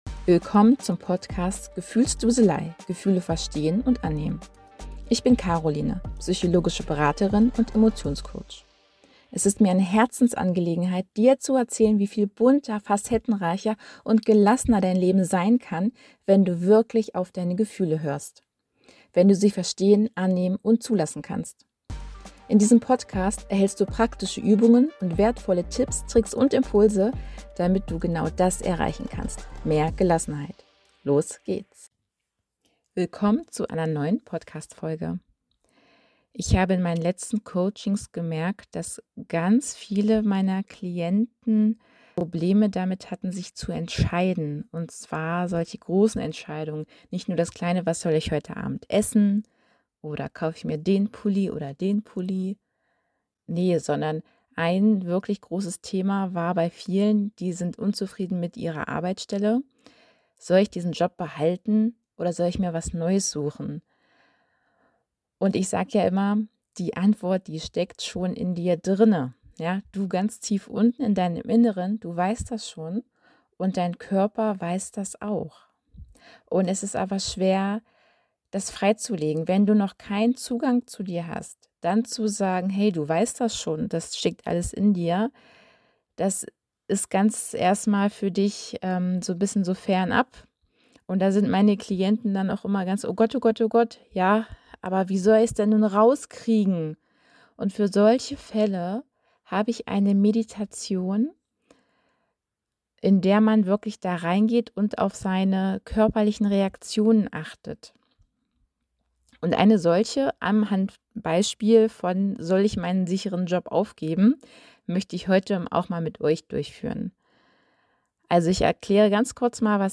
Was dich in dieser Folge erwartet: Eine geführte Meditation: Ich führe dich durch eine sanfte Meditation, die dir hilft, in dich hineinzufühlen und die Antworten deines Körpers zu entdecken.